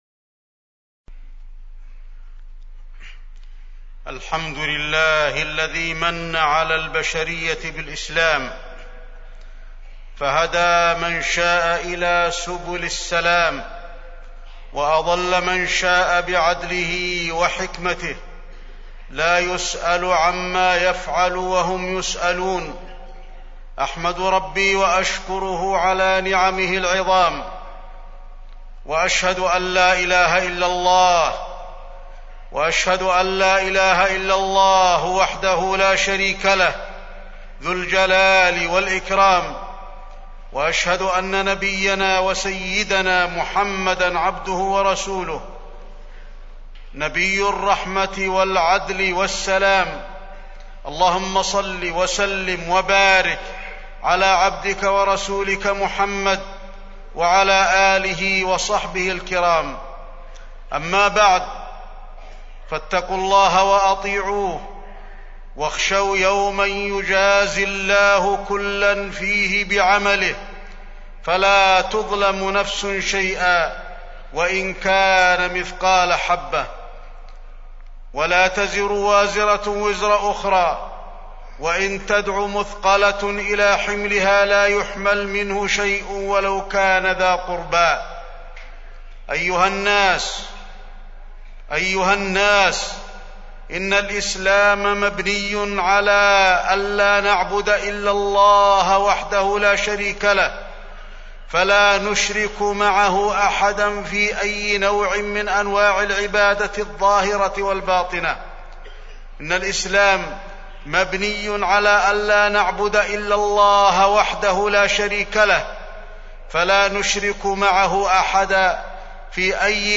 تاريخ النشر ٧ ذو القعدة ١٤٢٦ هـ المكان: المسجد النبوي الشيخ: فضيلة الشيخ د. علي بن عبدالرحمن الحذيفي فضيلة الشيخ د. علي بن عبدالرحمن الحذيفي مؤتمر القمة الإسلامية